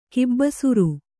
♪ kibbasuru